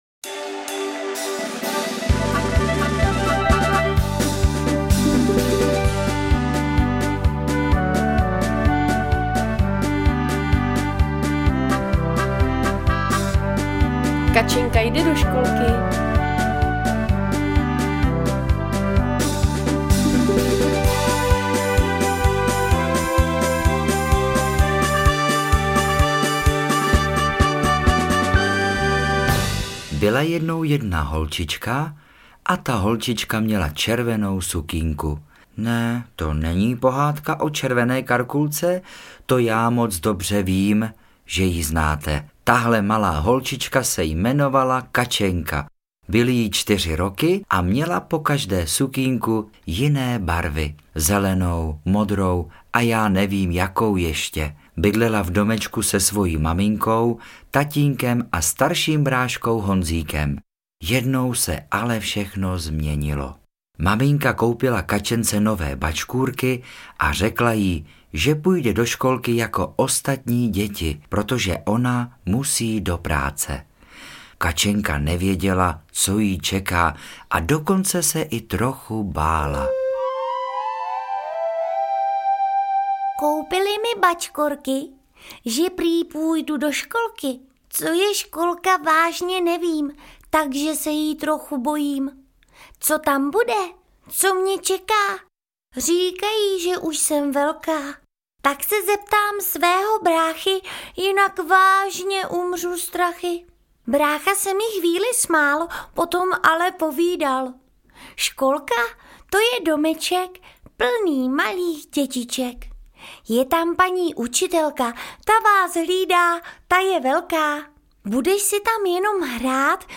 Povídání je střídáno krátkými písničkami.